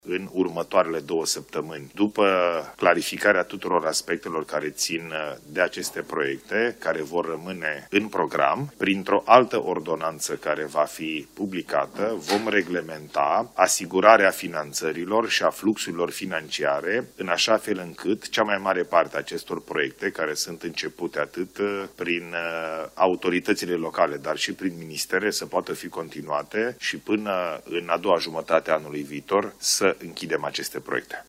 Premierul Ilie Bolojan, la începutul ședinței de Guvern: Vom reglementa asigurarea finanțărilor și a fluxurilor financiare pentru ca proiectele să poate fi continuate